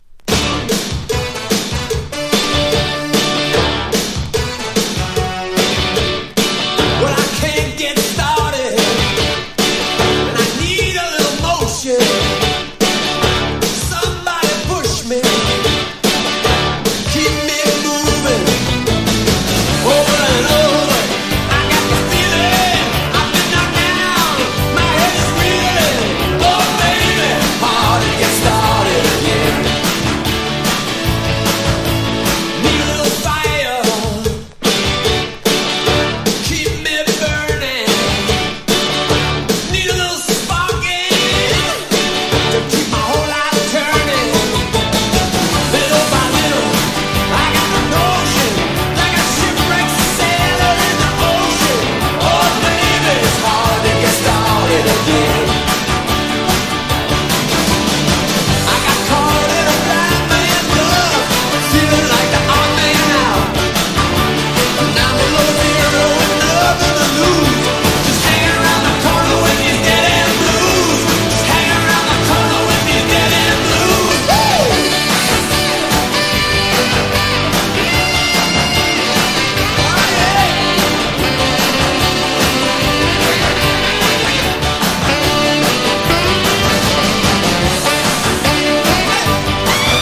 軽快でポップなロックンロール・ナンバー多数収録！
80’s ROCK / POPS